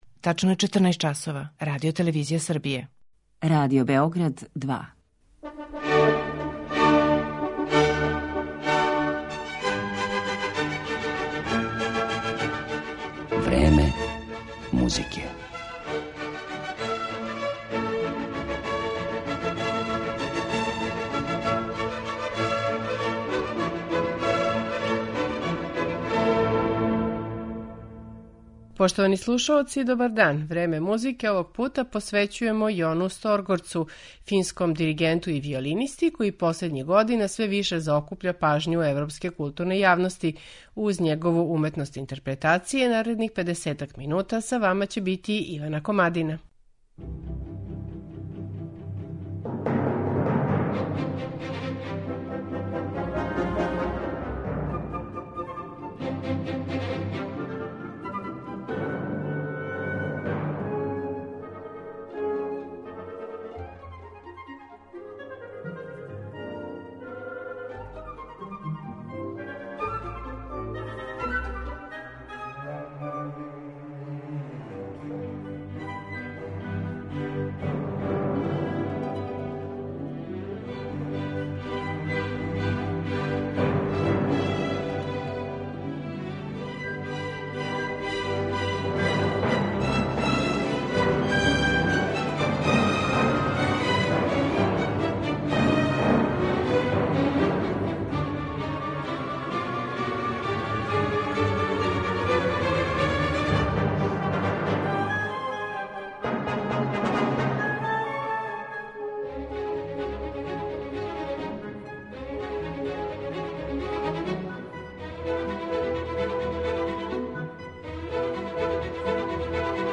фински диригент и виолиниста
интерпретацијама оркестарских дела